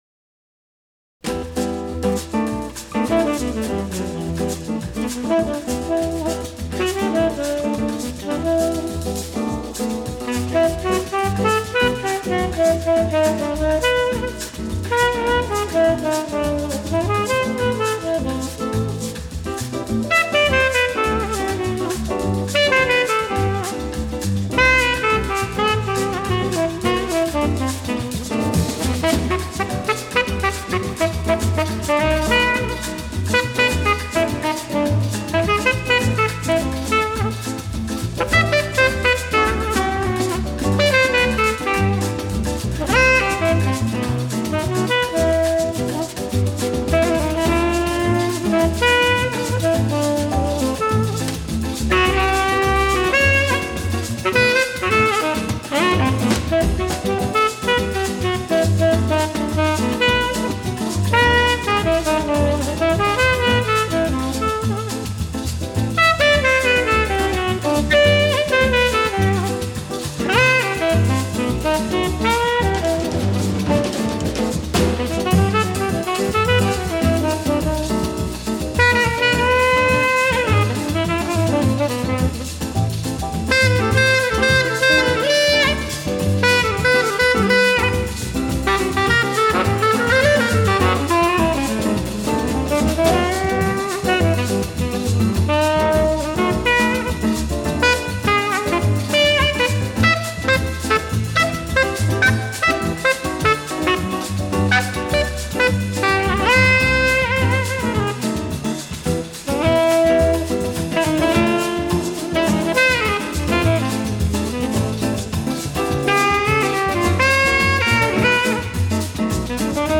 А вот еще прекрасная боссанова в исполнении супер дуэта.